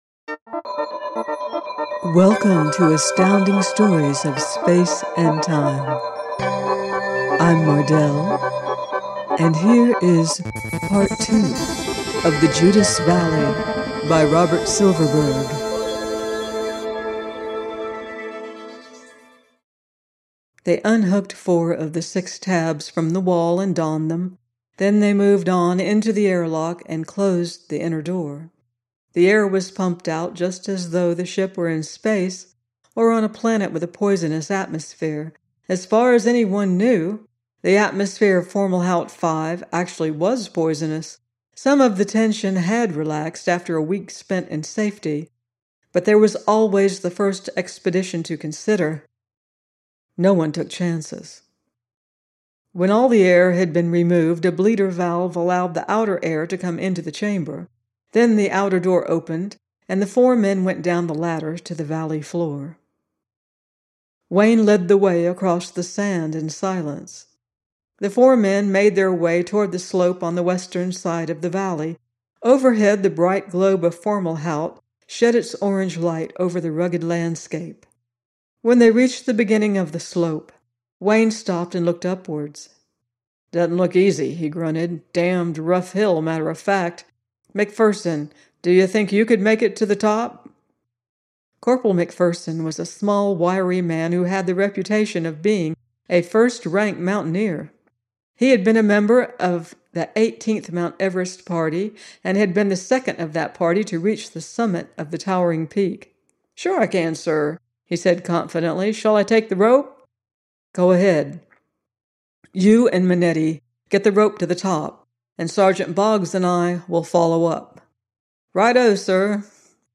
The Judas Valley – by Robert Silverberg - audiobook